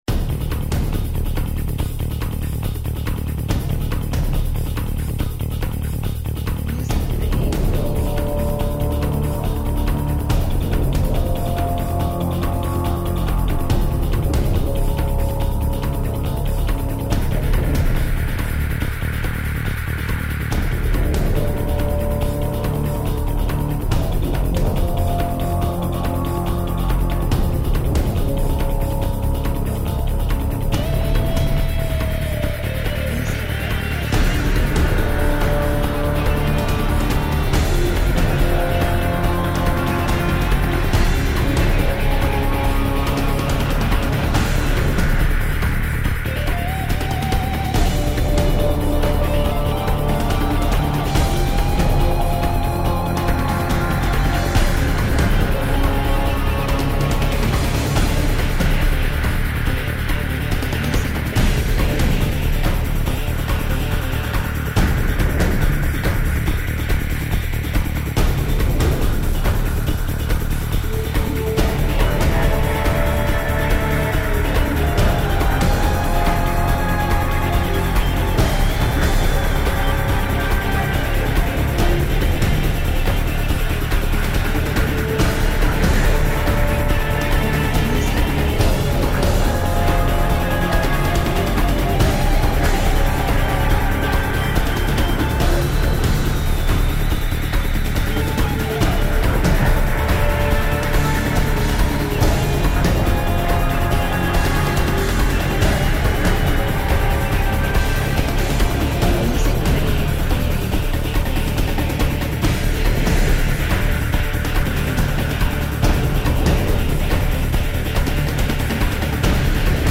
Cinematic royalty free music. Epic background music.